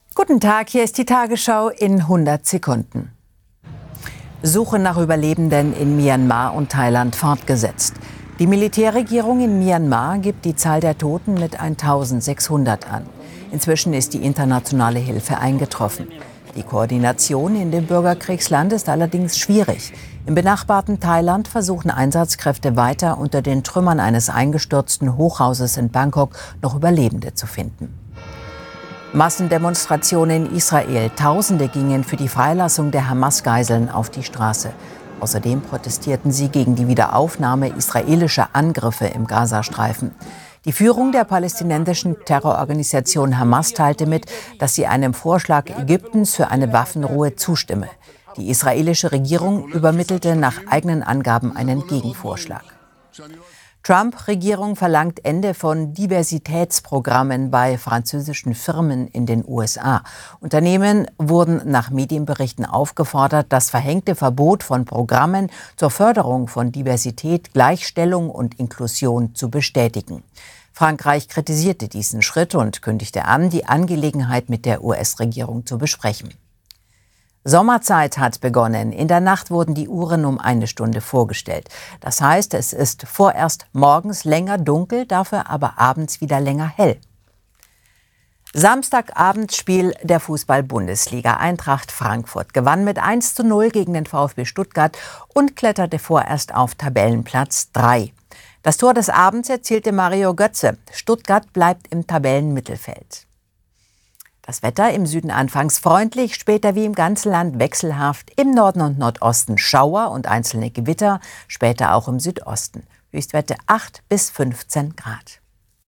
… continue reading 945 つのエピソード # Tägliche Nachrichten # Nachrichten # 100 Sekunden # Tagesschau